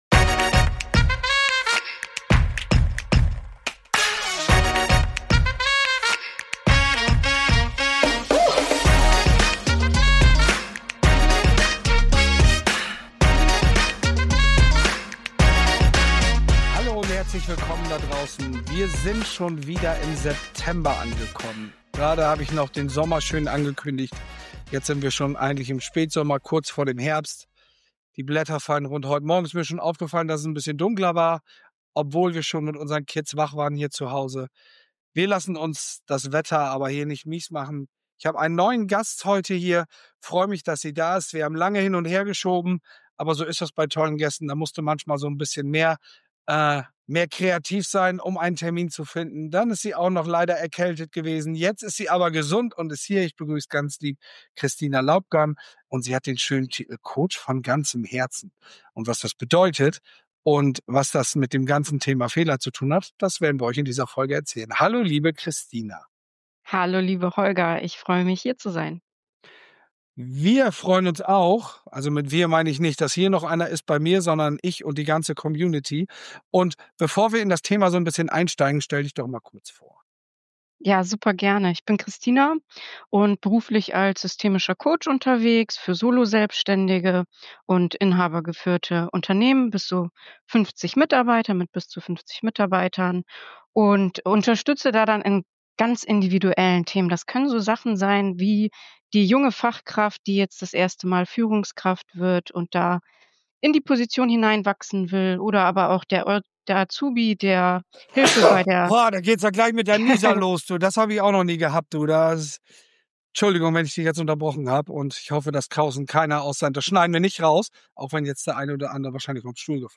Zwischen Bauchgefühl und Business: Wie Fehler echte Führung fördern - Interview